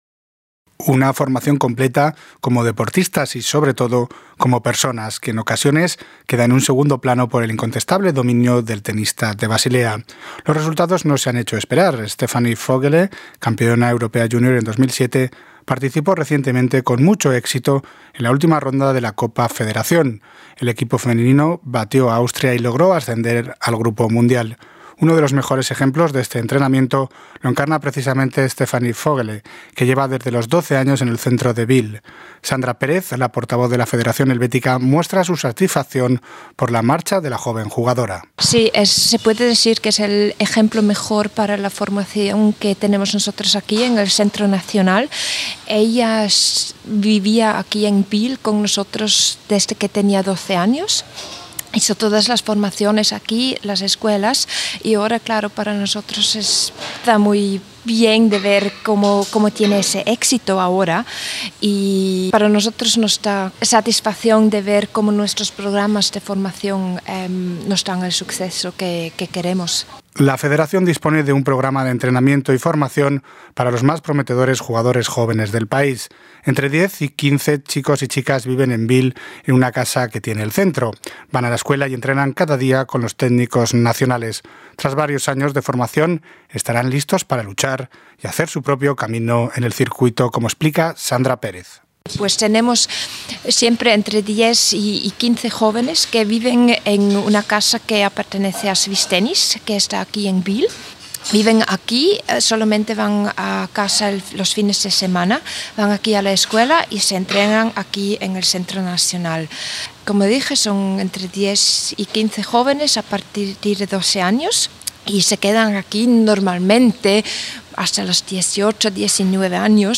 La Federación Suiza de Tenis prepara con mimo y paciencia a los jóvenes jugadores y jugadoras que deben suceder al tenista de Basilea en el futuro. Un reportaje